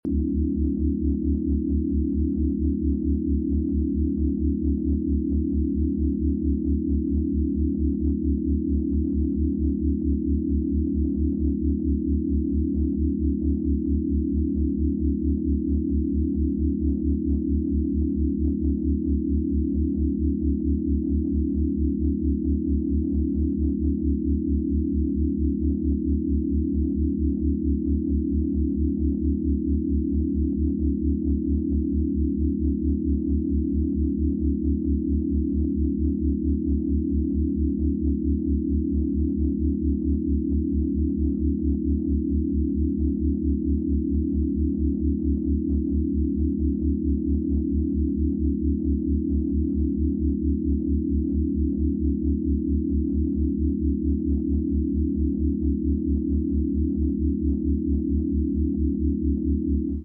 Get a MASSIVE Dopamine Release with 40 Hz Binaural Beats